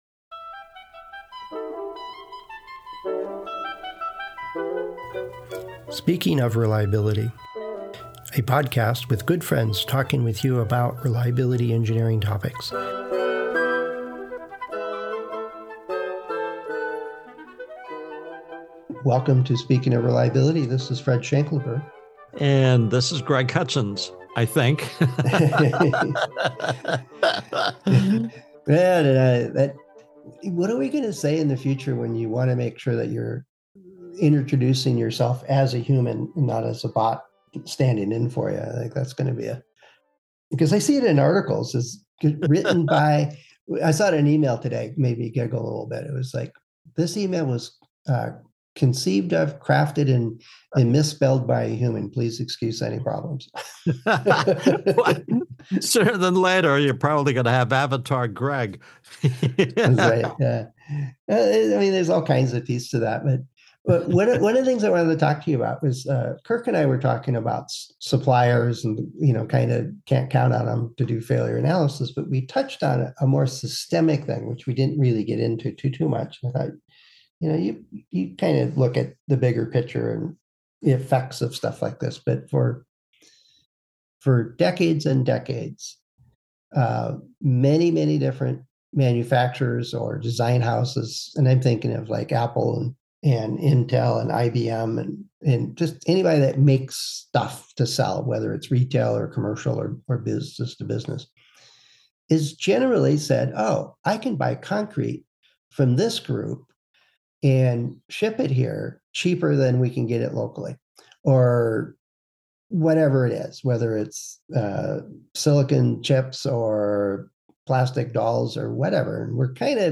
Friends Discussing Reliability Engineering Topics